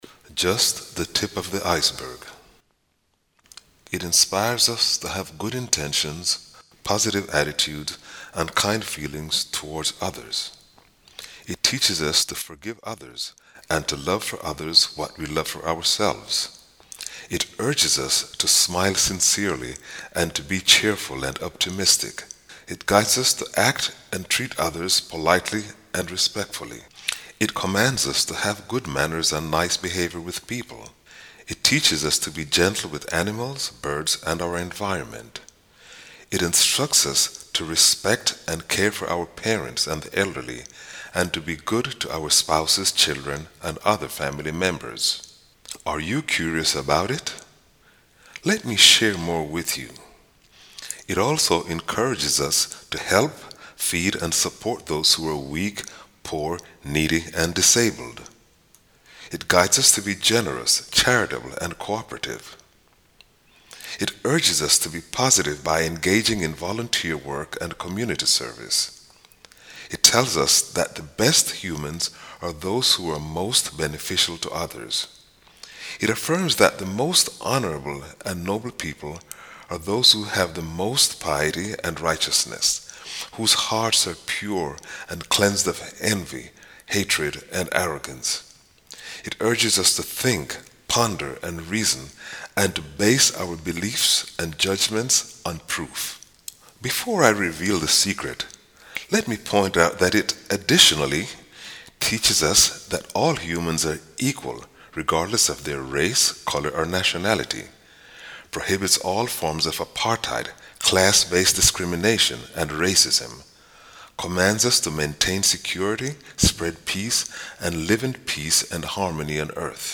Audio Book 1